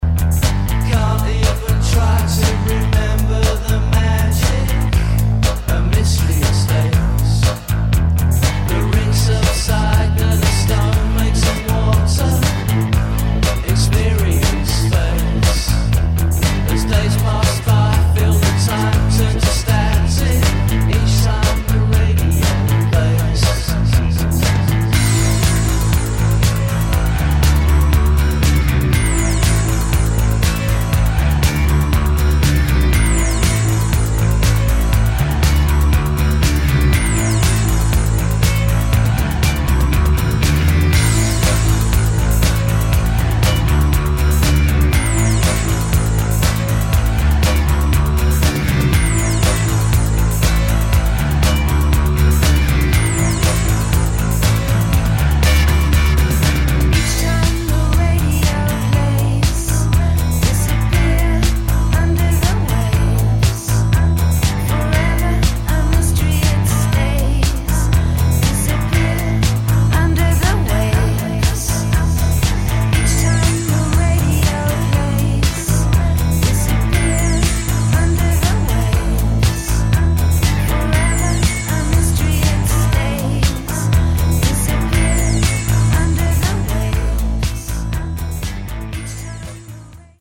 [ TECHNO | ELECTRO | DISCO ]